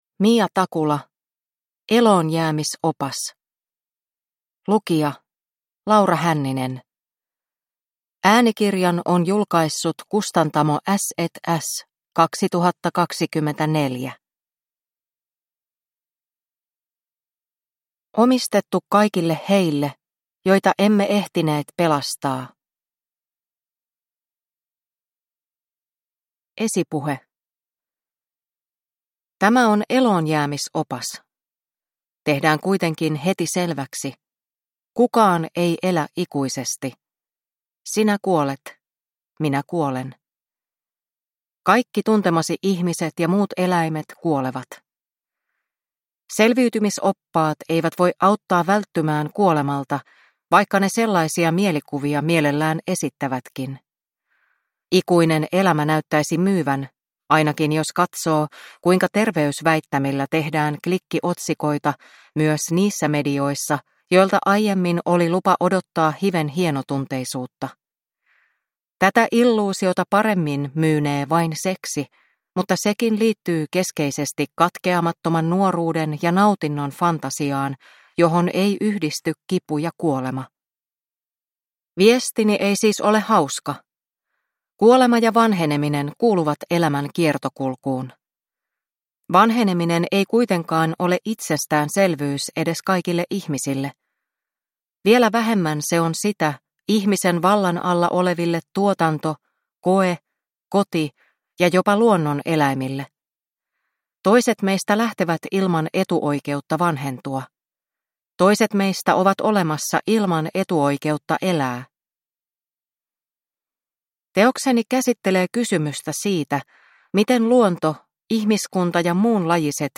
Eloonjäämisopas (ljudbok) av Mia Takula